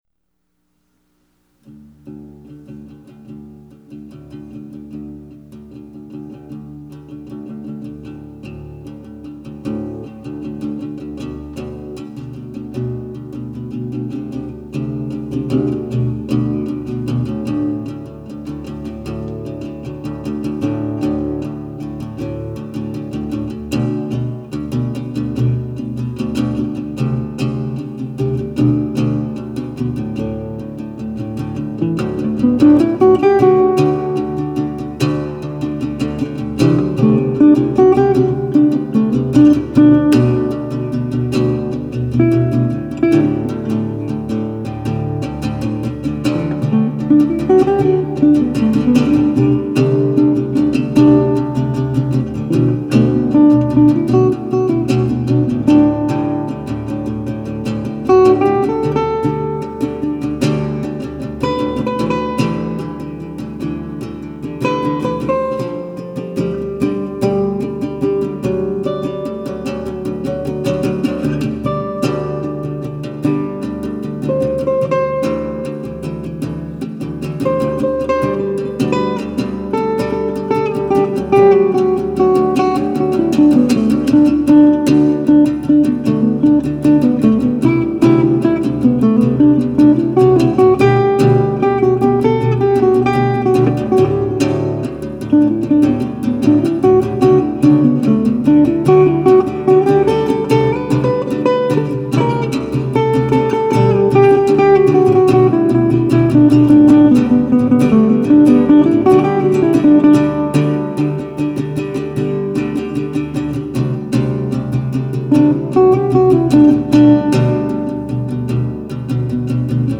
Dobro Duet Recorded in Gold Bar Washington. I went out back to the studio with my Dobro in hand and came back with this.
DobroDuet.mp3